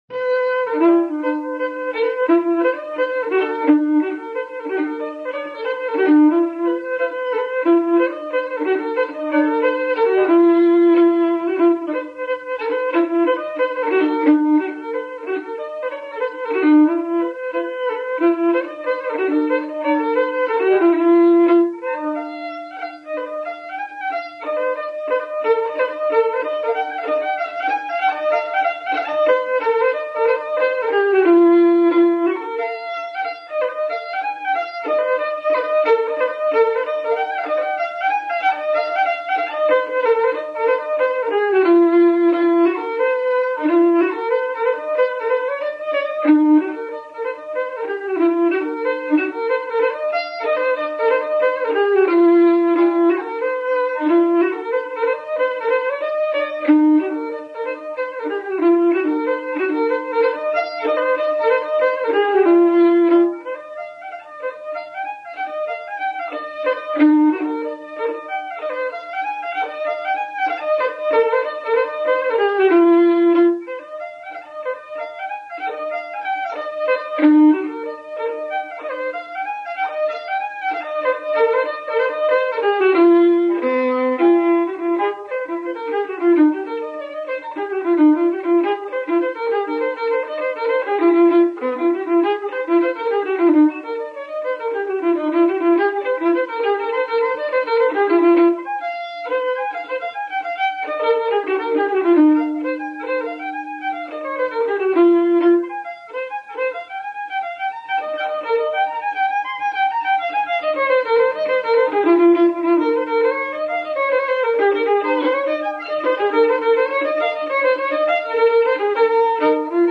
Fiddler 1910 – 1985
March and Jigs
From a private recording, undated.